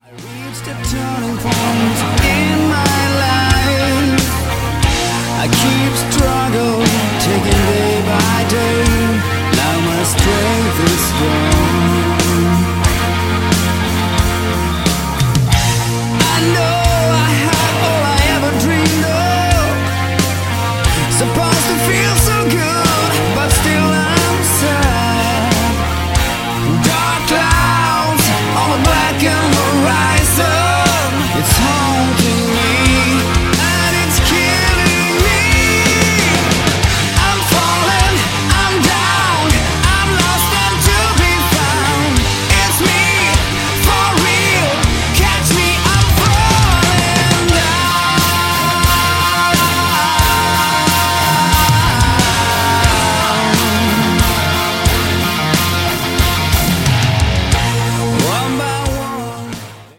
Guitars and Backing Vocals
Keyboards and vocals
hard rock